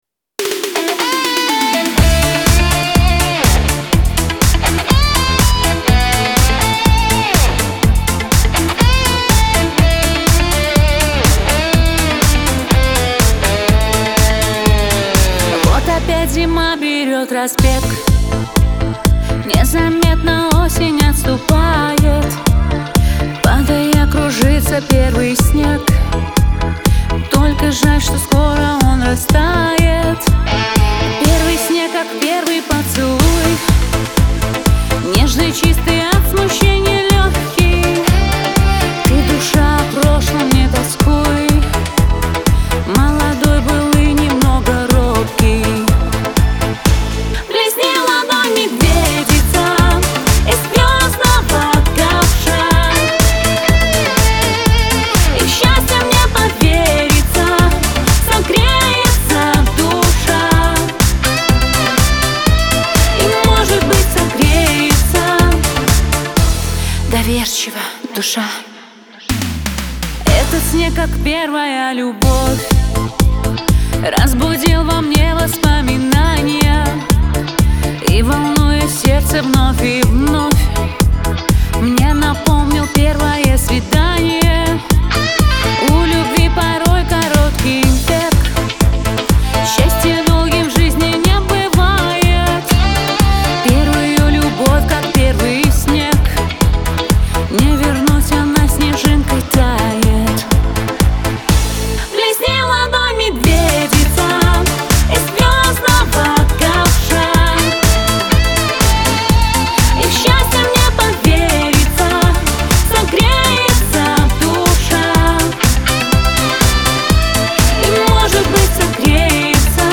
диско , pop
эстрада
dance